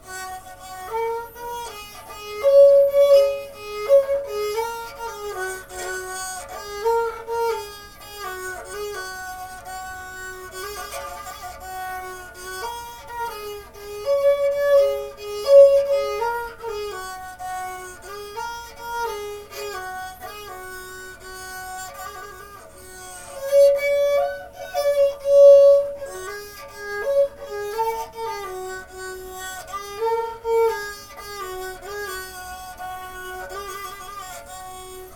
弓奏の楽器は音を継続して長く出し続けることができ、ビブラートを駆使して人の歌声に近い音を奏でることを得意とします。
今回は、試みに沙流地方のヤイサマネナ「即興歌」の旋律を演奏してみました。
カによる演奏例　ヤイサマネナ　MP3 700KB